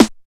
45 SNARE 2.wav